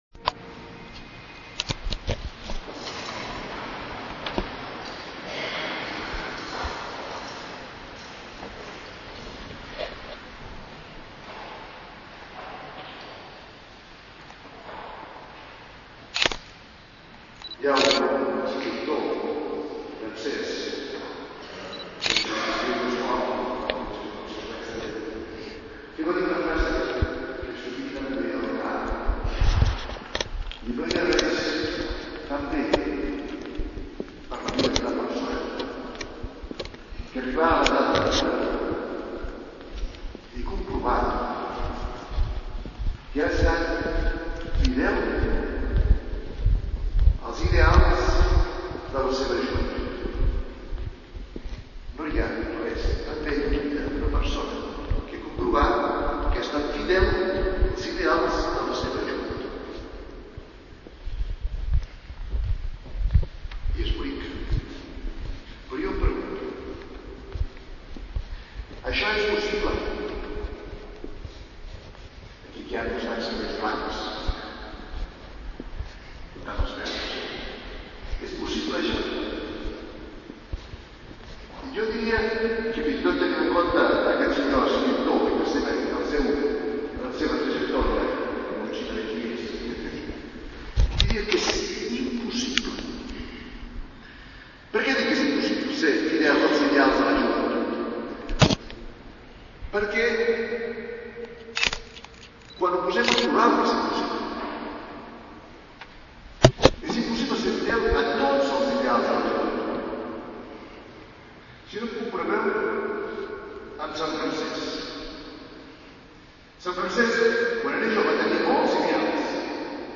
Homilia.mp3